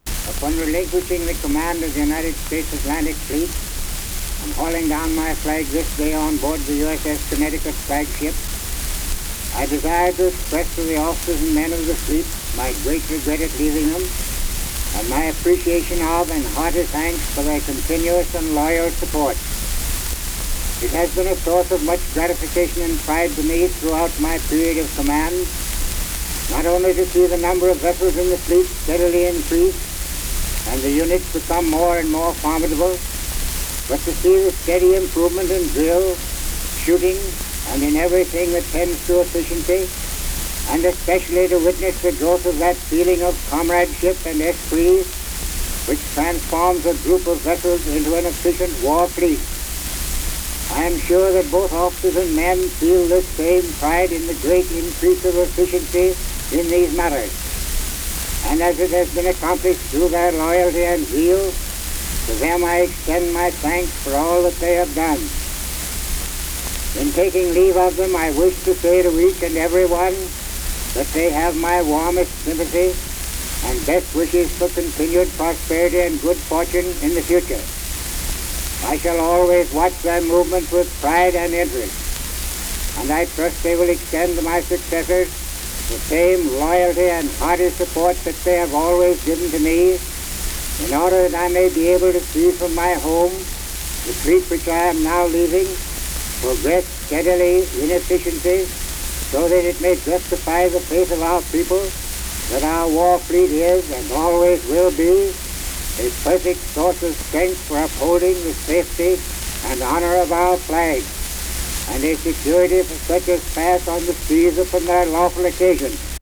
Robley Evans gives a farewell address to the U.S. Navy
Navy Material Type Sound recordings Language English Extent 00:01:56 Venue Note Recorded 1908 October 13.